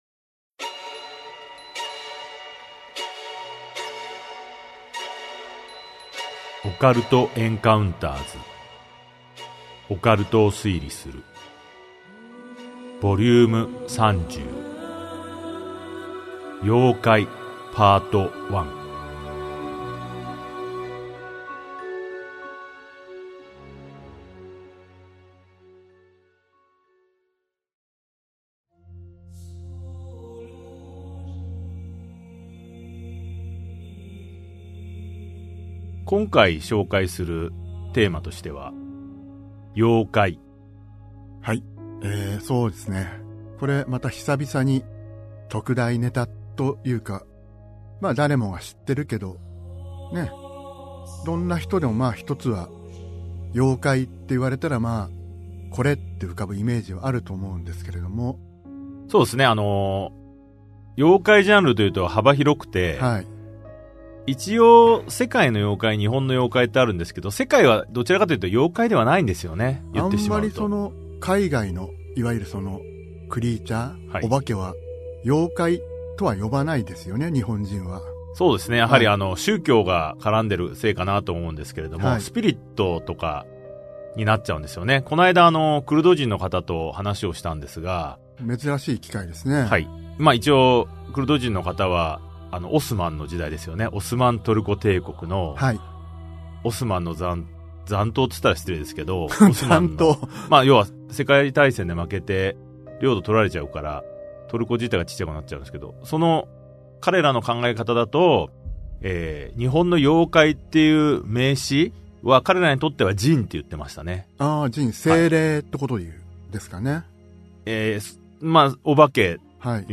[オーディオブック] オカルト・エンカウンターズ オカルトを推理する Vol.30 妖怪 1